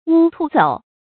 烏踆兔走 注音： ㄨ ㄘㄨㄣˊ ㄊㄨˋ ㄗㄡˇ 讀音讀法： 意思解釋： 指日月運行。